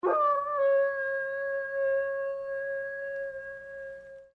"Big bad" beast heard